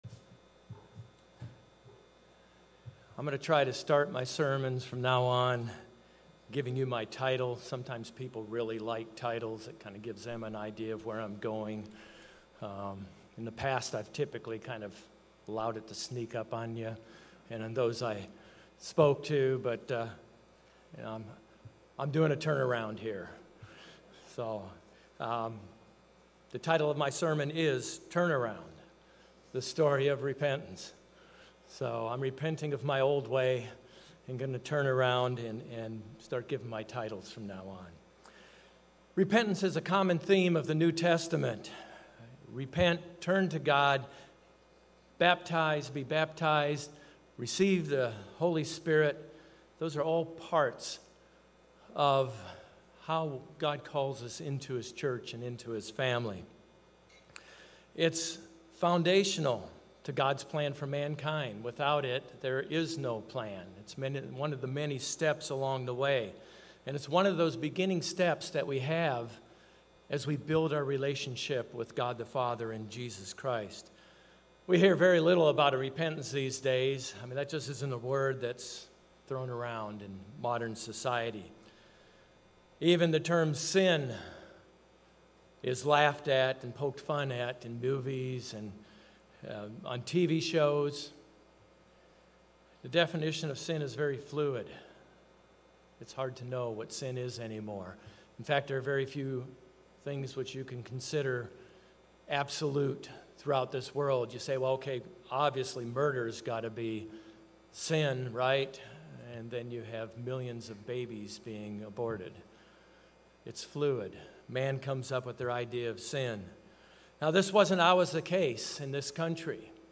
Given in Orlando, FL Jacksonville, FL